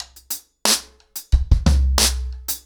ROOTS-90BPM.27.wav